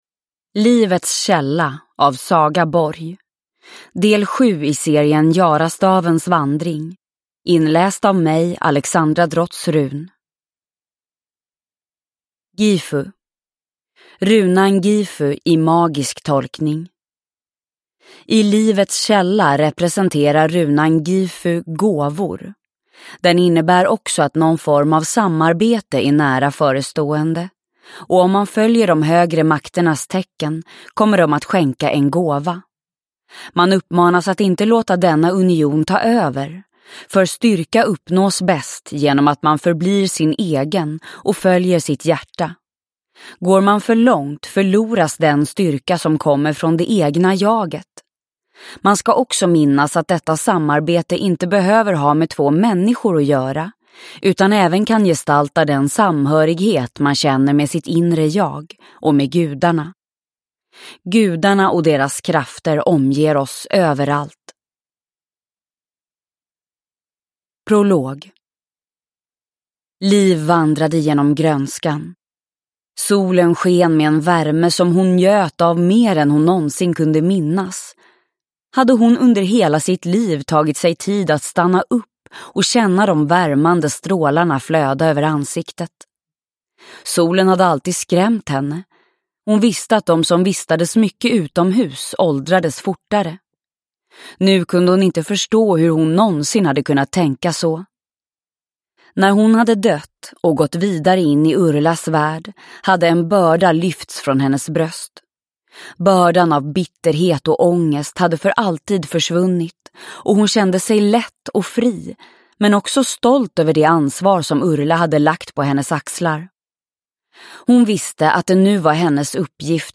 Livets källa – Ljudbok – Laddas ner